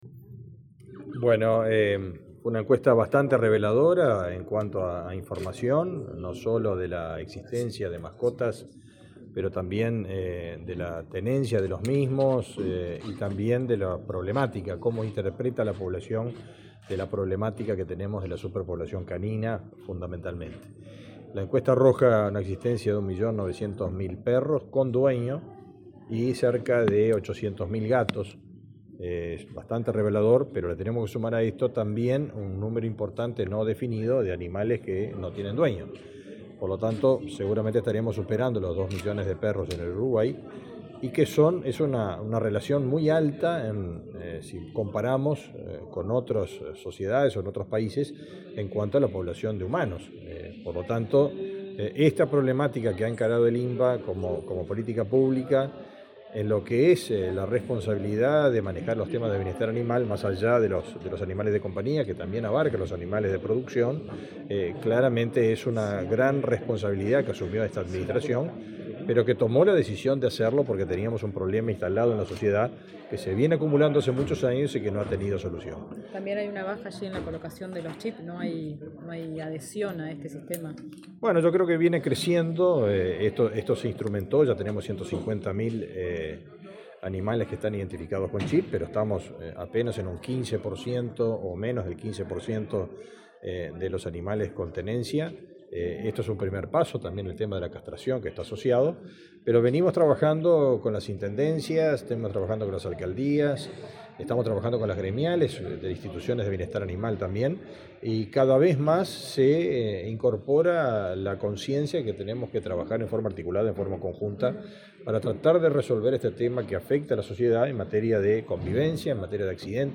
Declaraciones del ministro de Ganadería, Fernando Mattos
Declaraciones del ministro de Ganadería, Fernando Mattos 16/11/2023 Compartir Facebook X Copiar enlace WhatsApp LinkedIn Este jueves 16, el ministro de Ganadería, Agricultura y Pesca, Fernando Mattos, dialogó con la prensa en la sede ministerial, luego de presentar los resultados de una encuesta sobre tenencia y bienestar animal.